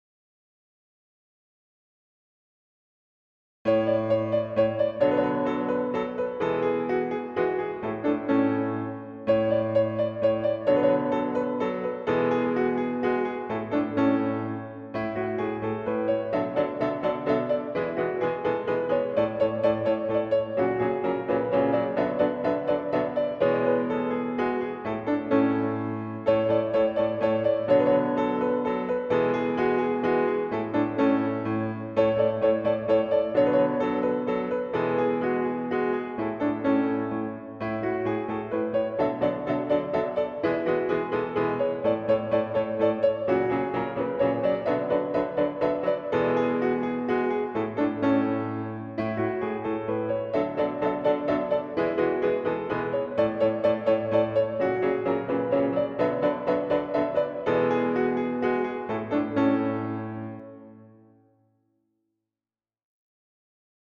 Klavier
minuetto n.3 in A major